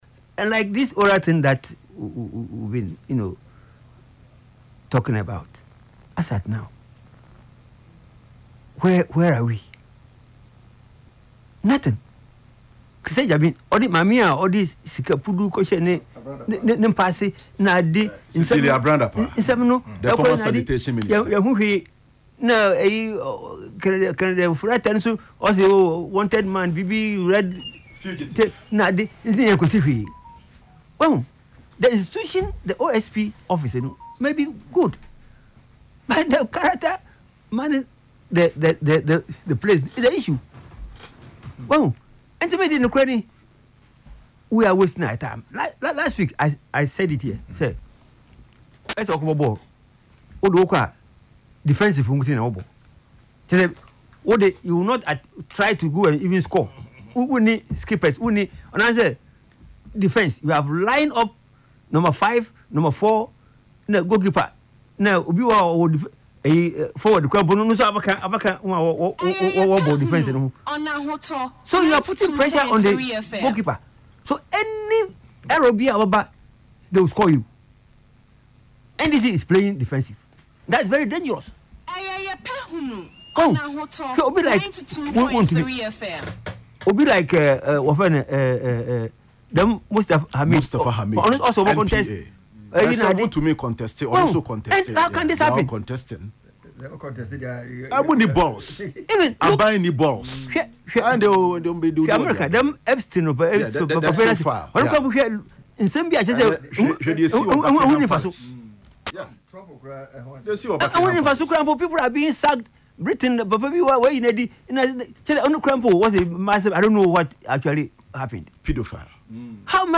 Speaking on Ahotor FM’s Yepe Ahunu programme on Saturday, April 18